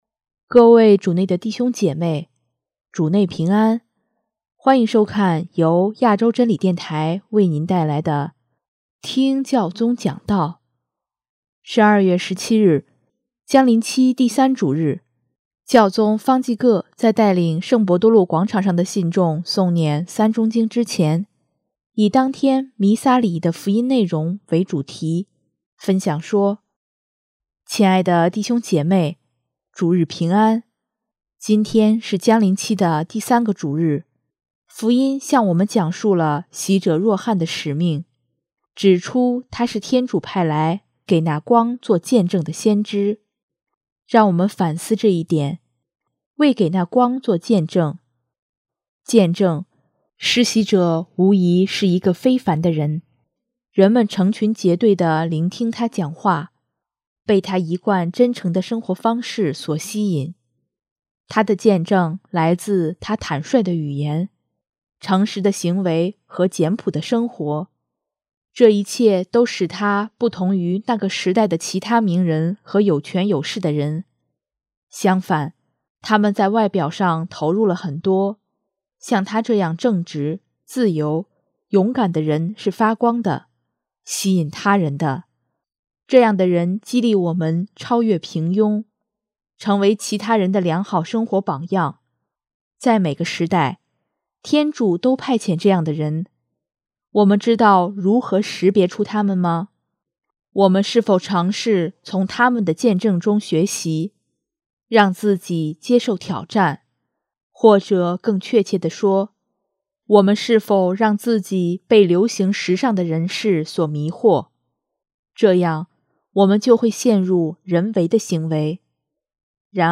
【听教宗讲道】|他是一盏灯，而光是永生的基督
12月17日，将临期第三主日，教宗方济各在带领圣伯多禄广场上的信众诵念《三钟经》之前，以当天弥撒礼仪的福音内容为主题，分享说：